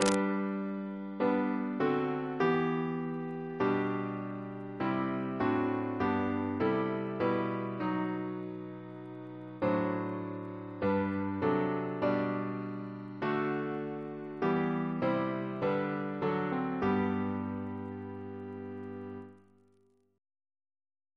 Double chant in G Composer: Robert Thomas Skarratt (1784-1860) Reference psalters: PP/SNCB: 87